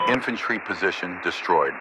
Added "infantry killed" radio messages
pilotKillInfantry5.ogg